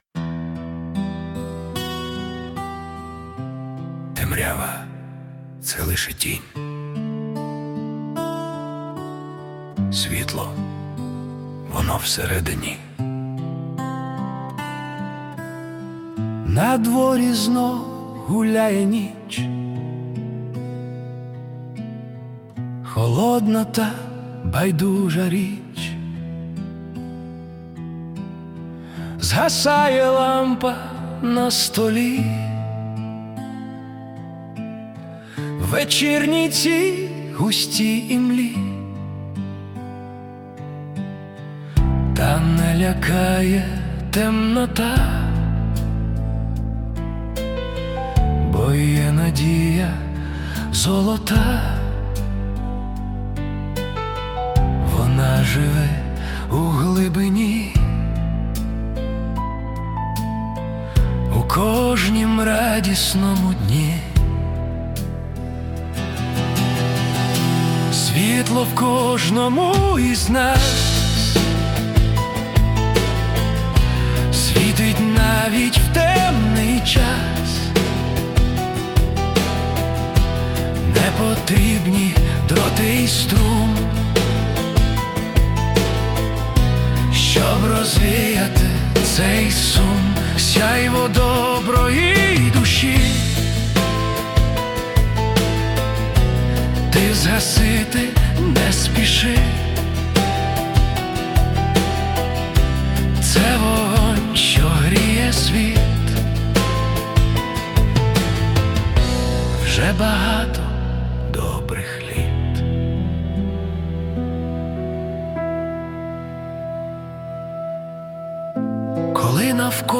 це глибока філософська балада